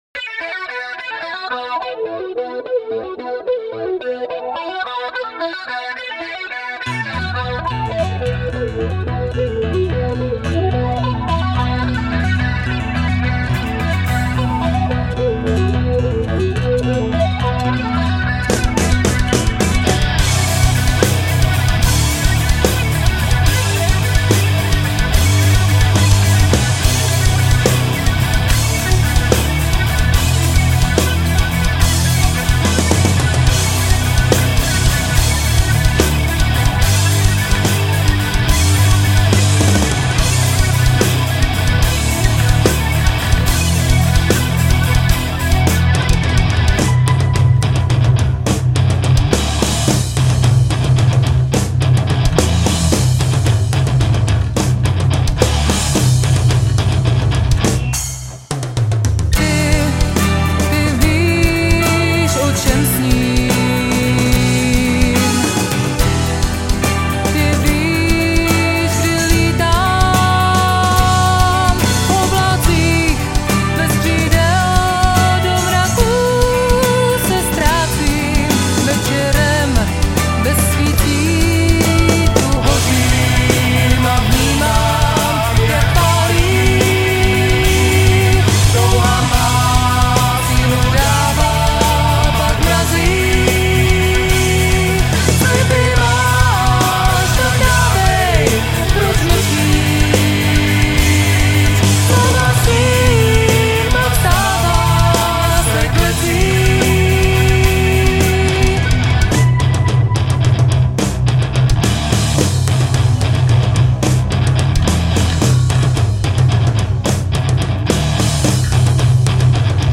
Žánr: Rock
baskytara, zpěv
bicí, perkuse